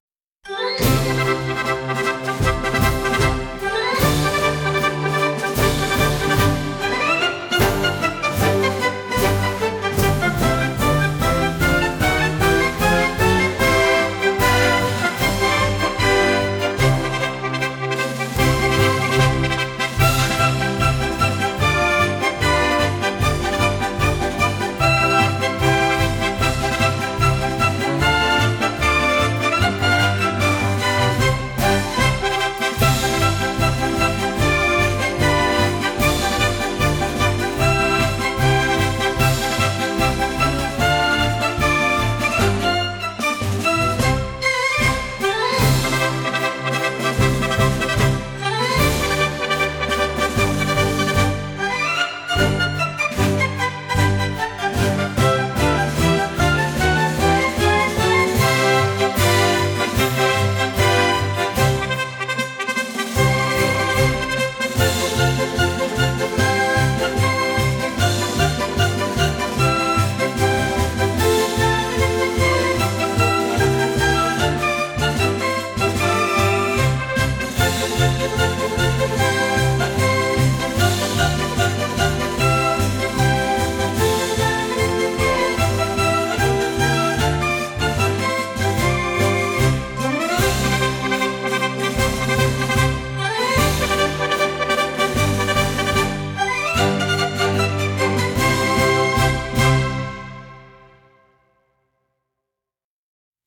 爽やかさを併せ持ったリコーダーとオルゴールの音色の音楽です。